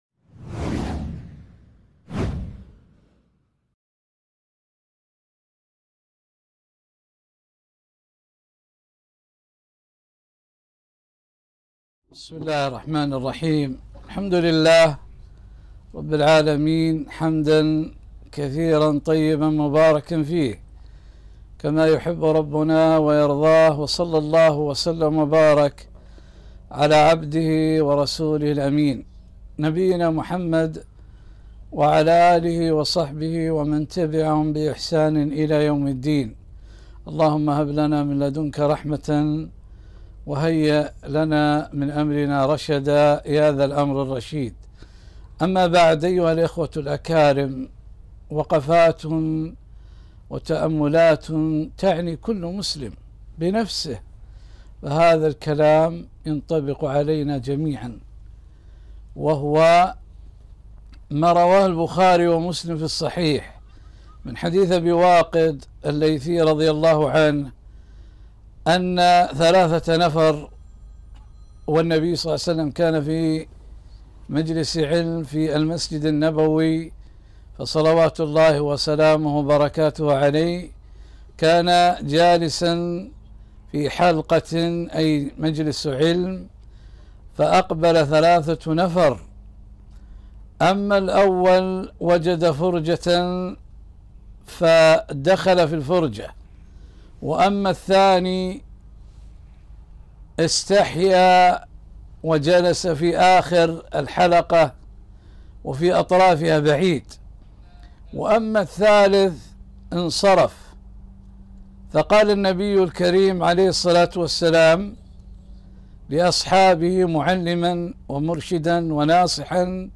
محاضرة - وقفات و تأملات مع حديث ابي واقد الليثي رضي الله عنه